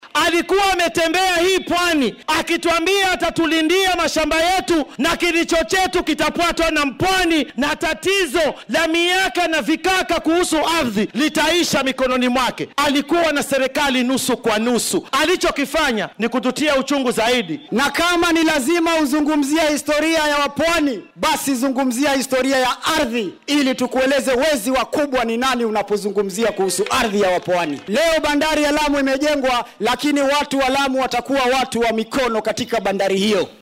Hadalladan ayay ka jeediyeen fagaaraha Burhani ee deegaan baaramaneedka Mvita ee ismaamulka Mombasa.